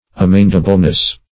Meaning of amendableness. amendableness synonyms, pronunciation, spelling and more from Free Dictionary.